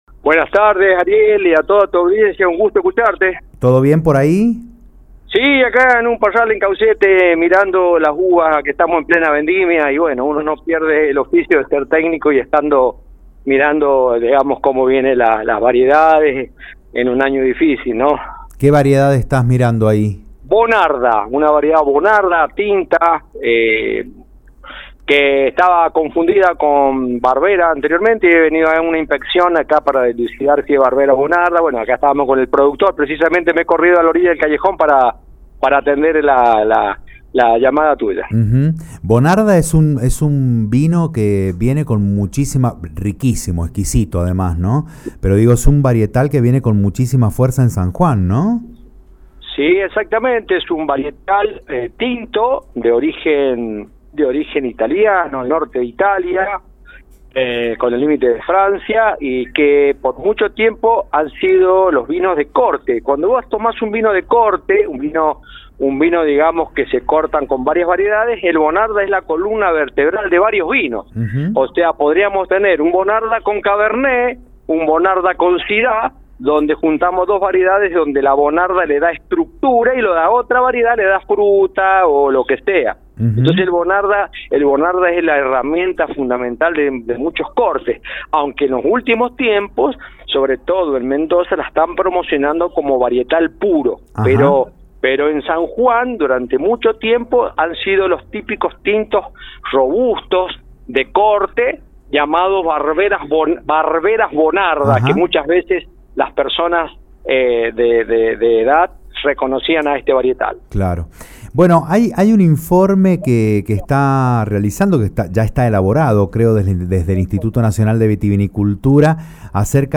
Escuchá la entrevista completa al vicepresidente del INV, Hugo Carmona, acá: